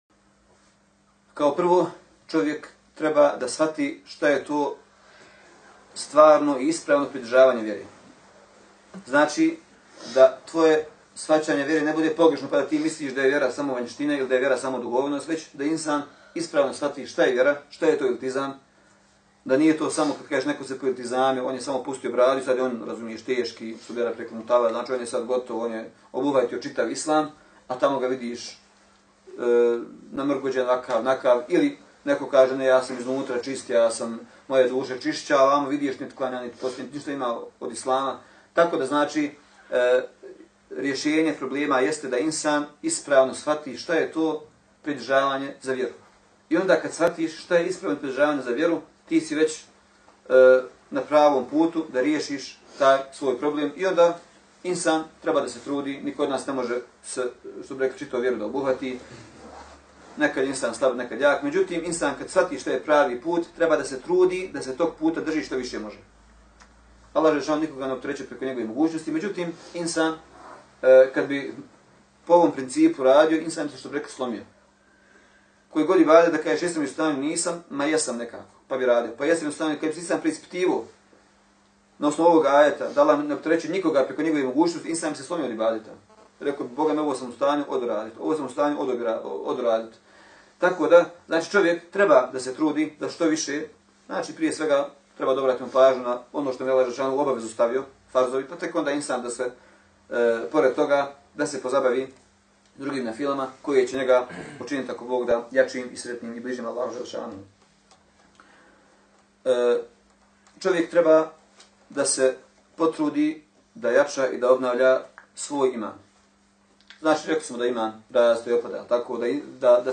Poslušajte mp3 isječak sa predavanja vezan za ovo pitanje